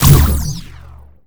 sci-fi_weapon_blaster_laser_boom_01.wav